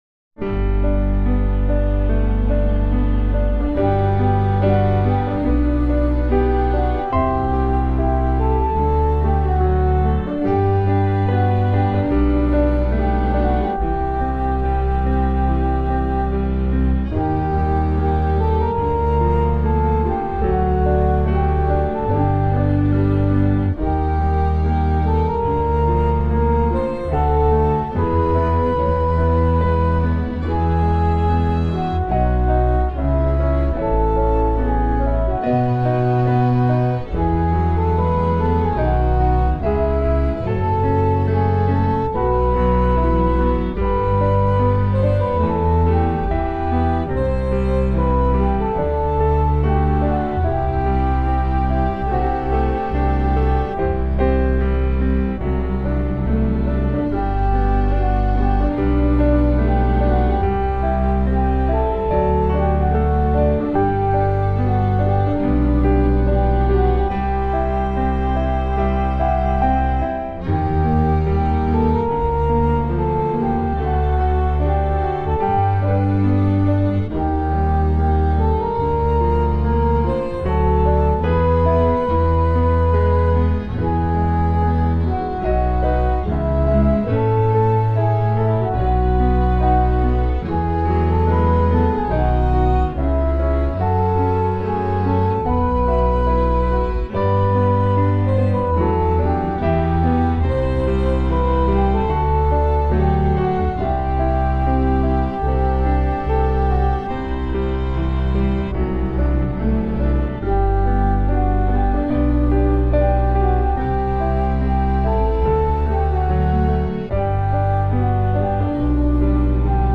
What is interesting is the key changing from G to Eb to E and back to G in each verse refrain cycle.  While that sounds over the top it actually flows pretty well.